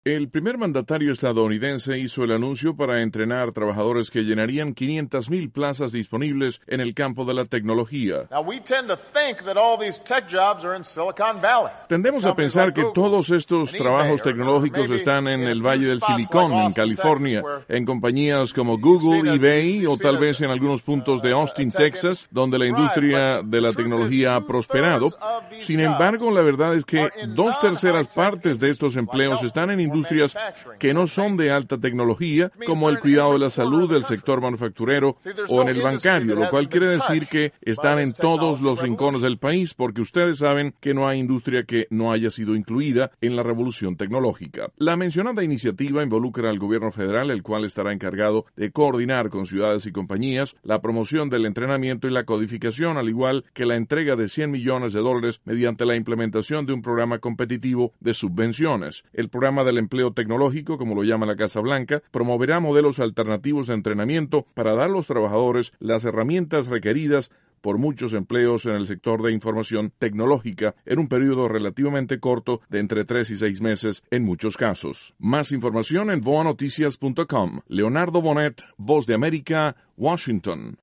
informa desde Washington.